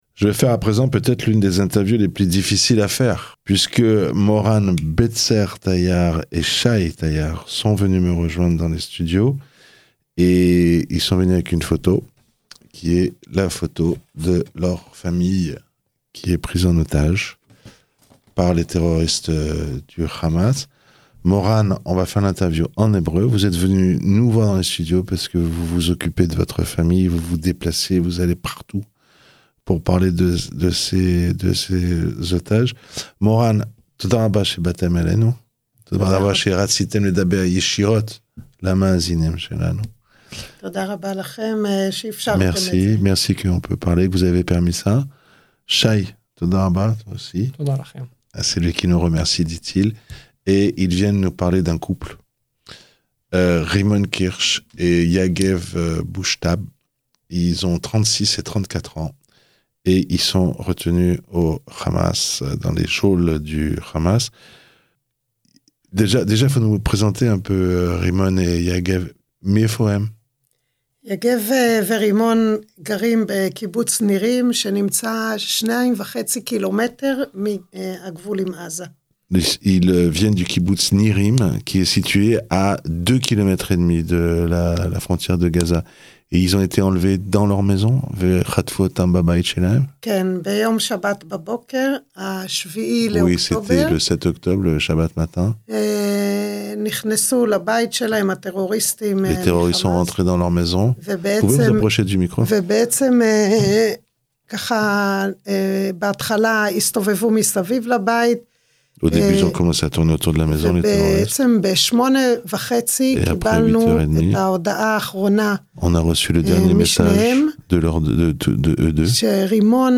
Des familles d'otages viennent témoigner dans le studios de Radio Shalom.